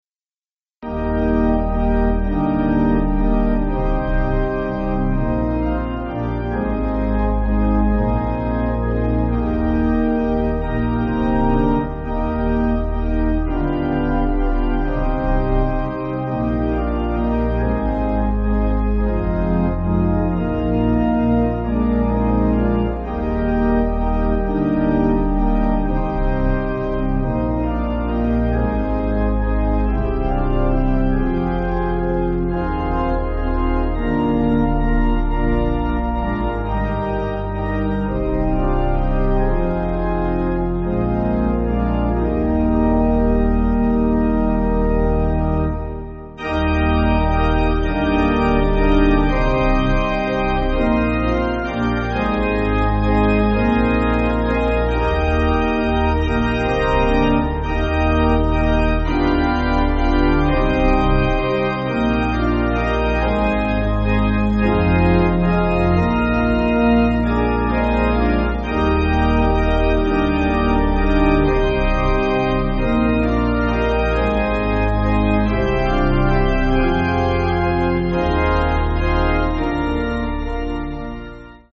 Organ
Accompaniment only